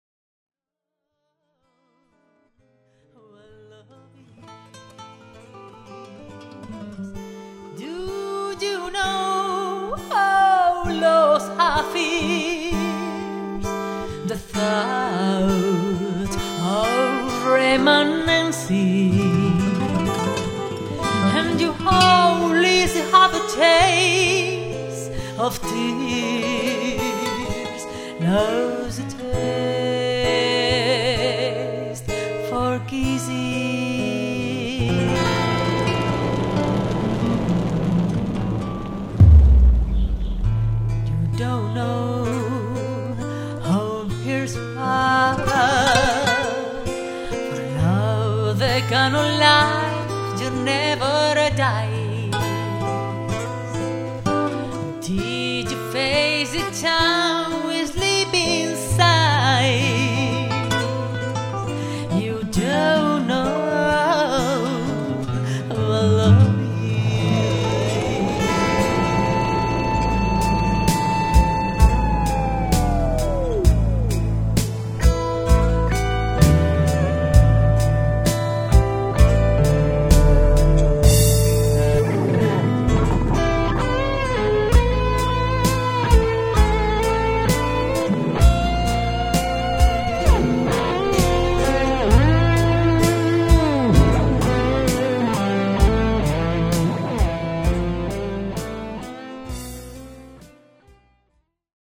Voz y Coros
Guitarras
Studio